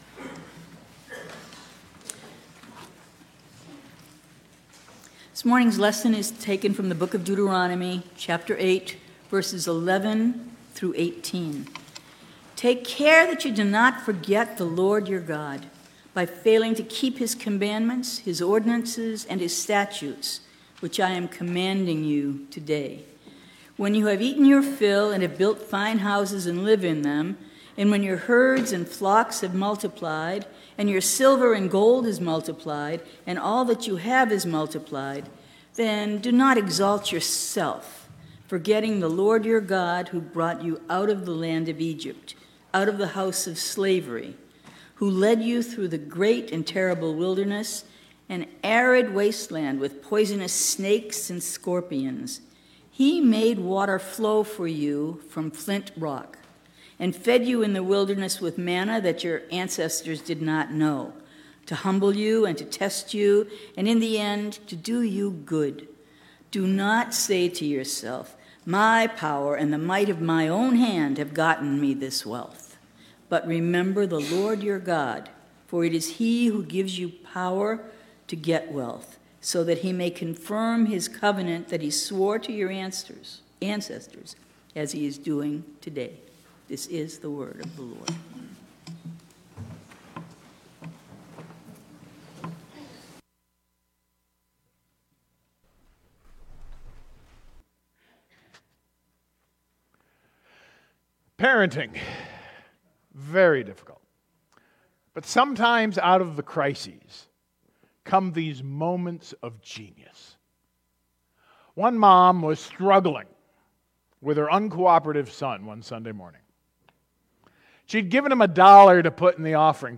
Scripture-Reading-and-Sermon-Nov.-6-2022.mp3